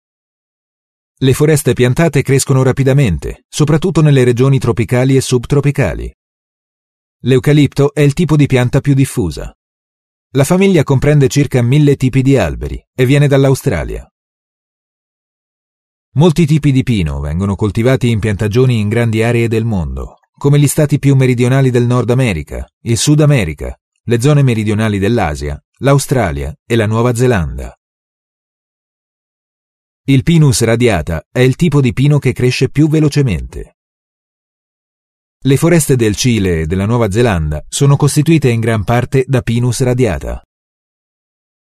The versatility of his voice enables him to provide many varying styles, accents and tones from mild sophisticated and corporate to a rich and sexy.
Sprecher italienisch.
Sprechprobe: eLearning (Muttersprache):
italian voice over artist.